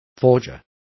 Also find out how falsificador is pronounced correctly.